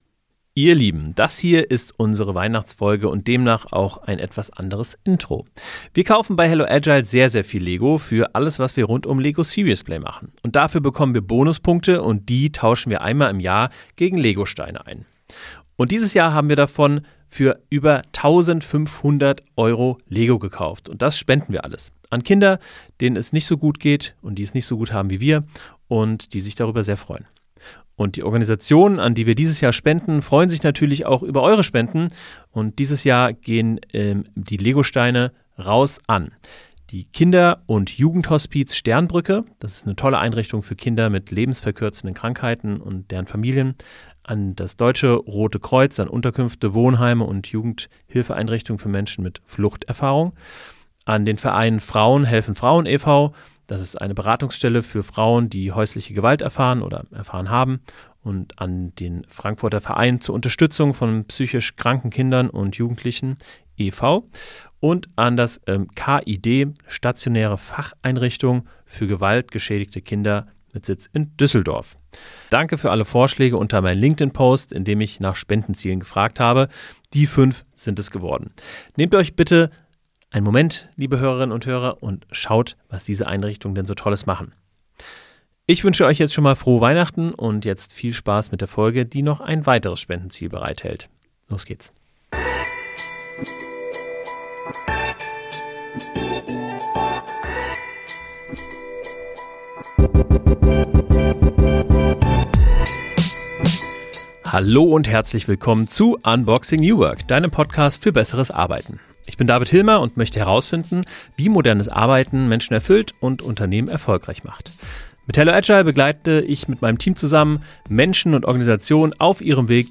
Es geht um Verantwortung, Selbstwirksamkeit, Ehrenamt, Finanzierung von Non-Profits und um die Frage, was New Work eigentlich bedeutet, wenn man es ernst meint. Ein ruhiges, ehrliches Gespräch über Bildung, Arbeit und die Zukunft, die wir gerade gestalten.